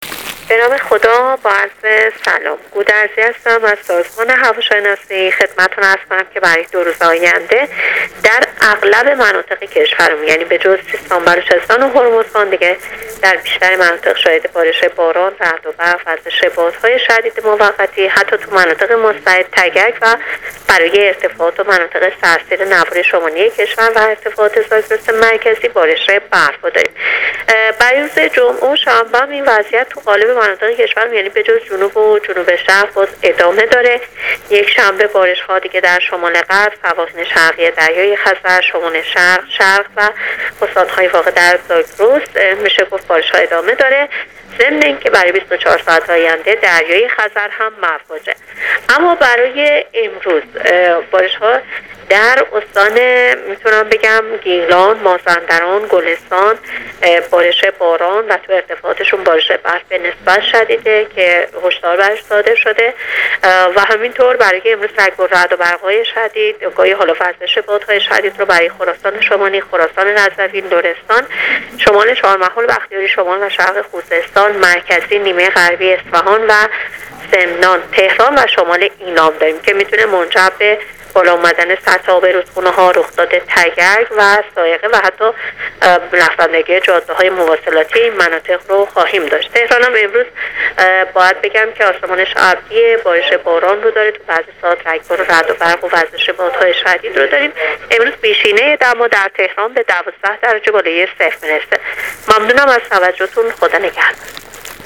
گزارش رادیو اینترنتی از آخرین وضعیت آب و هوای ۲۰ فروردین ۱۳۹۹